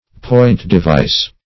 Point-device \Point`-de*vice"\, Point-devise \Point`-de*vise"\,